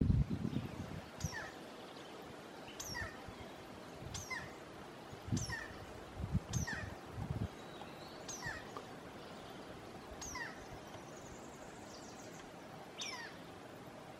Carpintero Lomo Blanco (Campephilus leucopogon)
Nombre en inglés: Cream-backed Woodpecker
Localidad o área protegida: La Estancita
Condición: Silvestre
Certeza: Vocalización Grabada